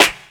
Clap(TOOMP (4).wav